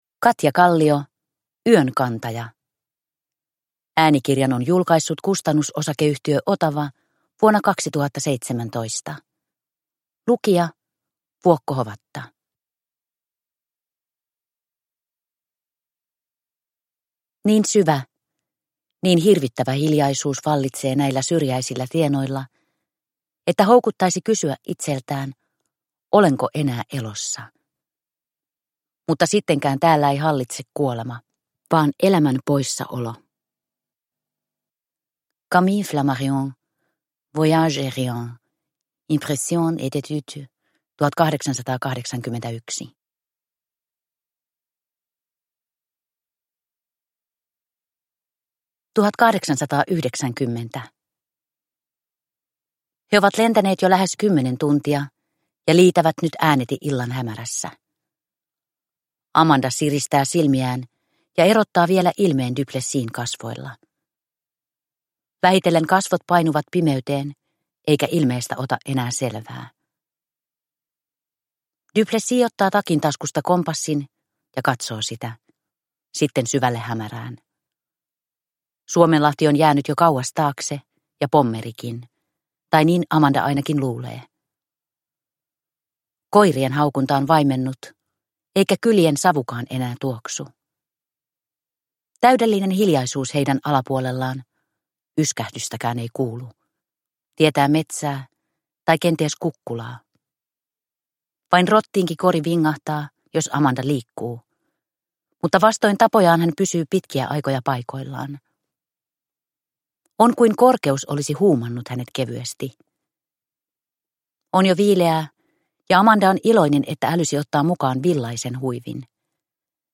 Yön kantaja – Ljudbok